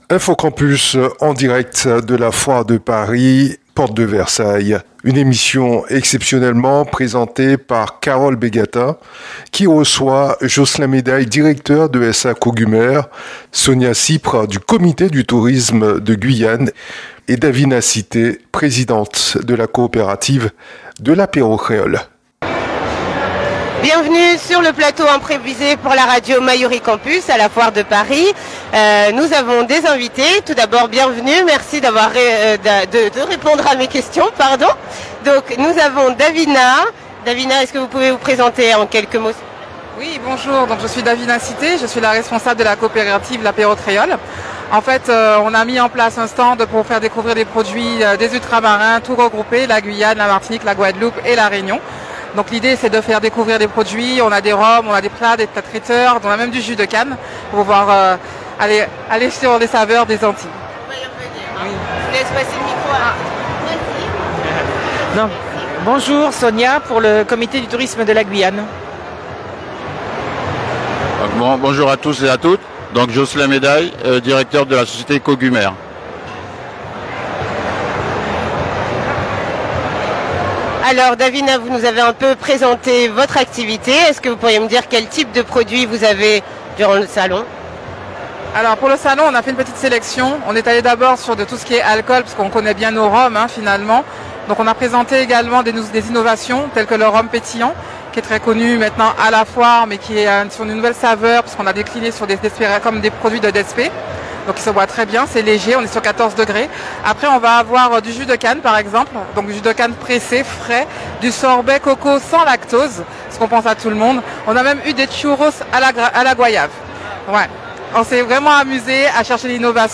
Radio Mayouri Campus en direct depuis la foire de Paris, porte de Versailles.